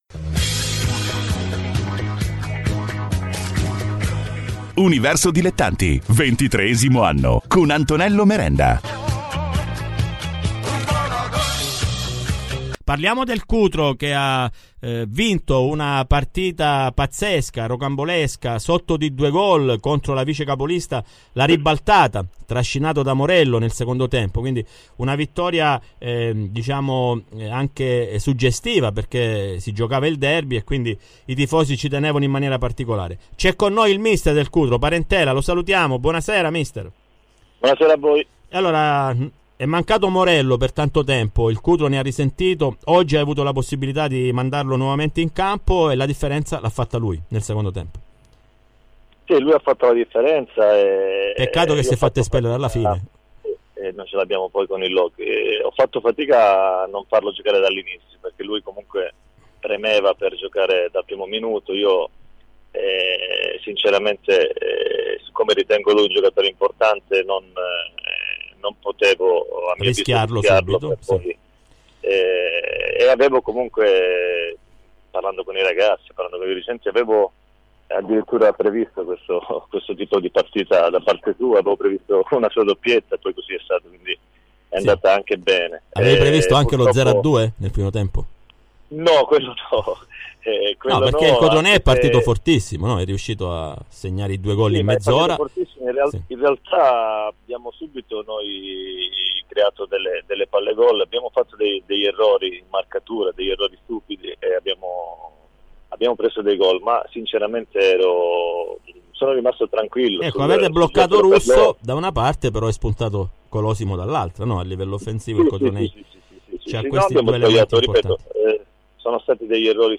Le interviste ai protagonisti di Universo Dilettanti